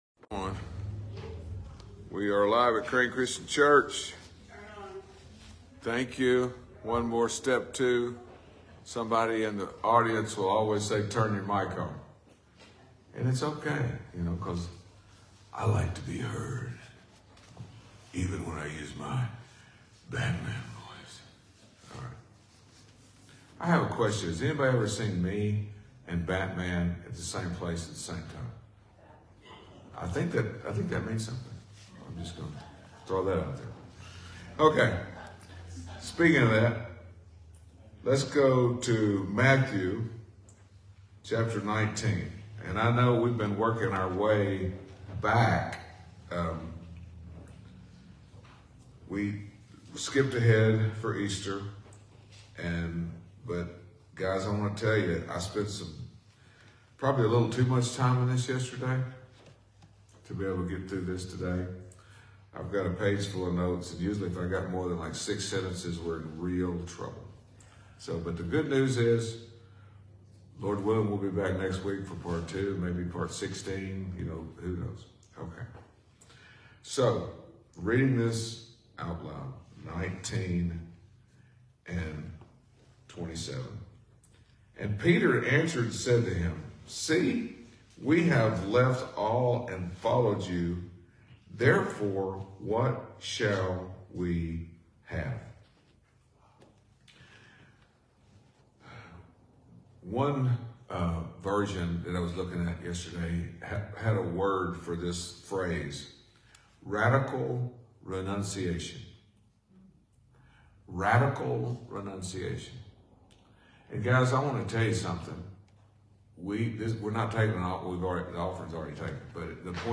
Sunday Sermon 4-14-24